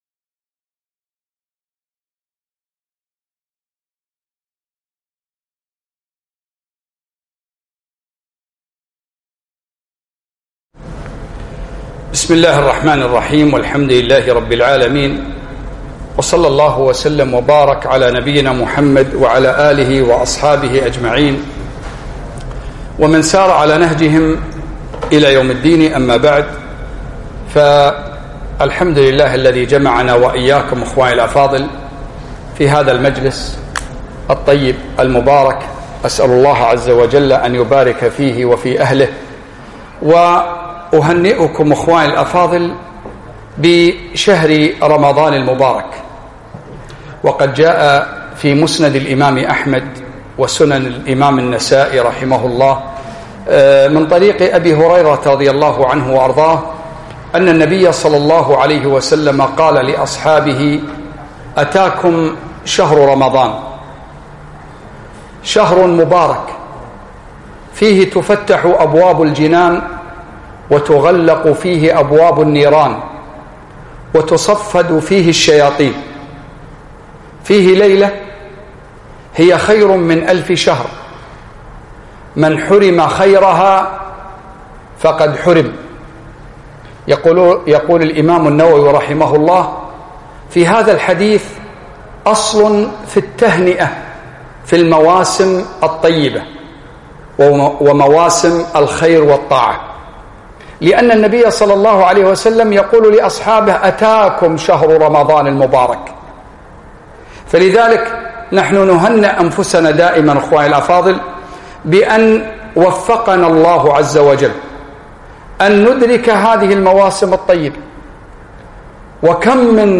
كلمة - شهر رمضان عبادة وتربية
ألقيت بعد التراويح